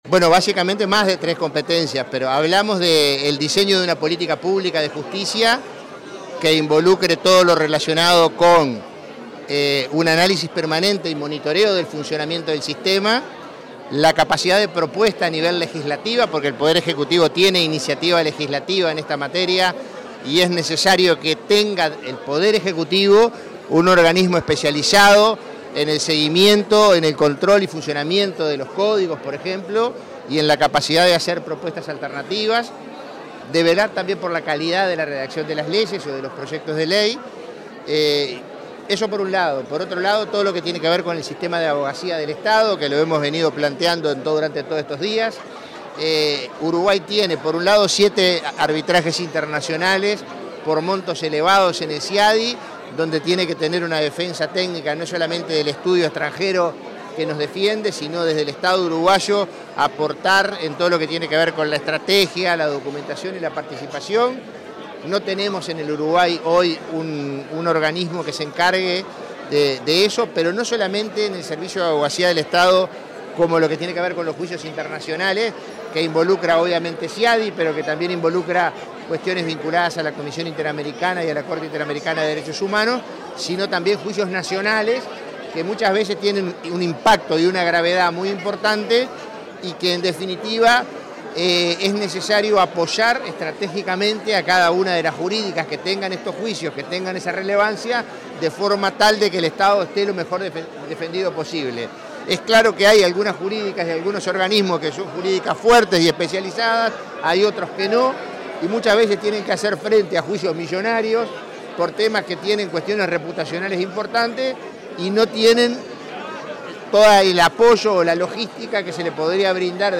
Declaraciones del prosecretario de la Presidencia, Jorge Díaz
El prosecretario de la Presidencia de la República, Jorge Díaz, dialogó con los medios de prensa tras participar en el seminario internacional La
diaz_prensa.mp3